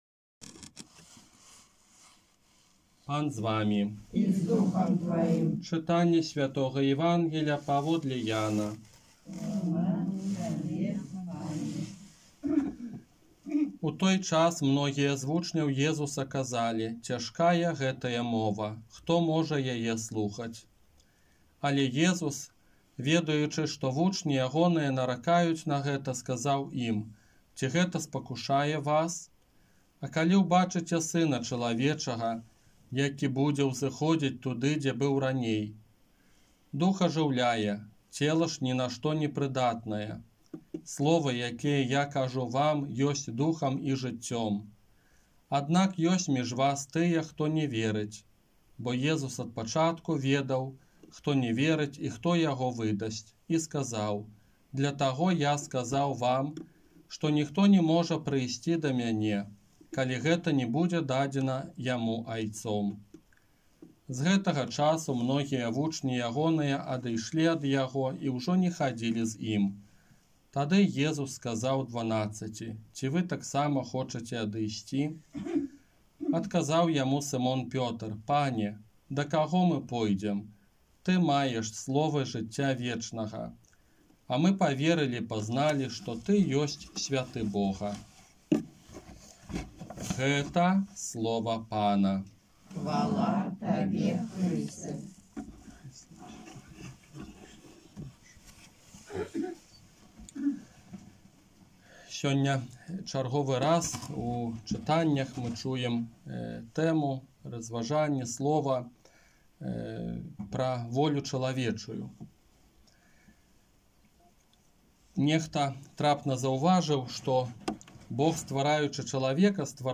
ОРША - ПАРАФІЯ СВЯТОГА ЯЗЭПА
Казанне на дваццаць першую звычайную нядзелю